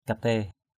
/ka-te̞:/ (d.) lễ Katé (một lễ hội dân gian tổ chức vào đầu tháng 7 lịch Chăm).